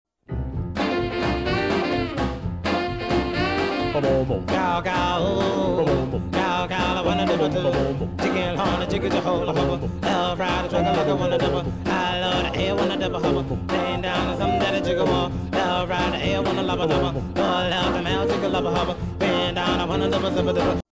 early rock/doo-wop song